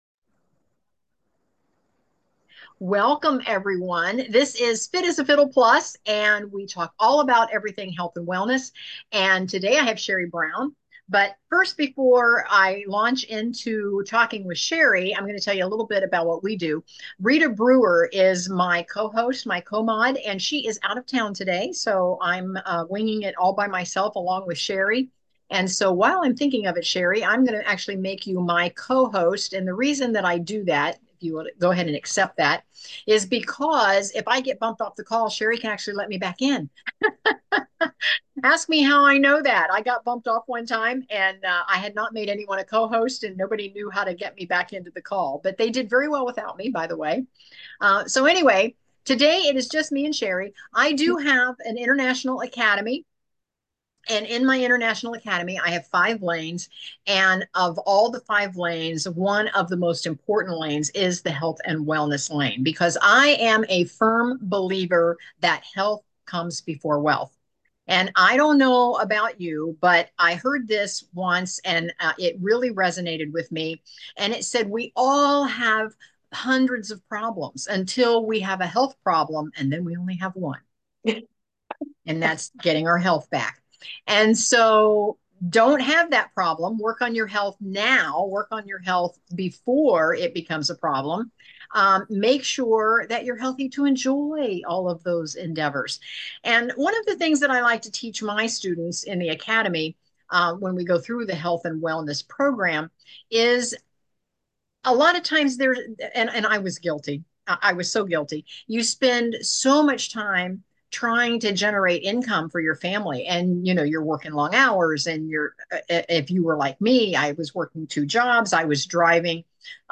HEALTH AND WELLNESS REPLAY